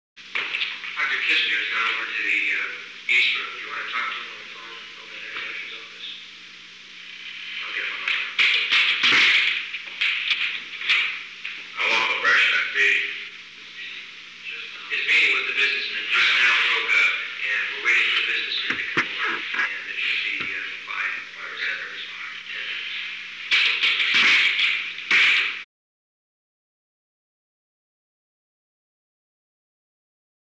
Secret White House Tapes
Location: Oval Office
The President met with an unknown man.